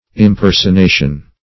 Impersonation \Im*per`son*a"tion\, Impersonification
impersonation.mp3